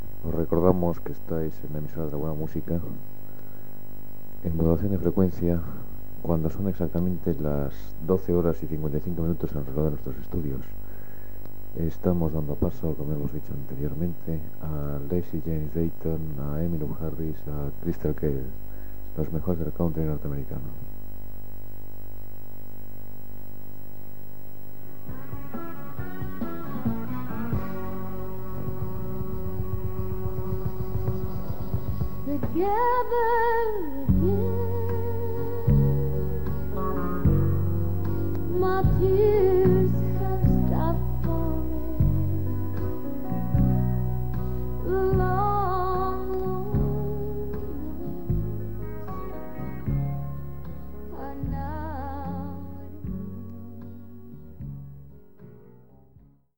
Identificació de l'emissora, hora i presentació d'un tema musical.
FM